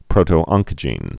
(prōtō-ŏnkə-jēn, -ŏngkə-)